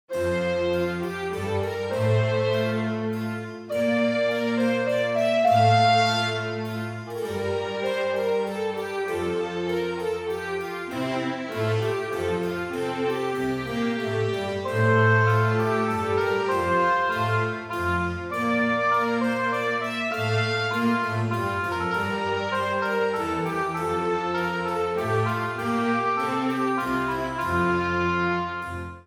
minuetoA.mp3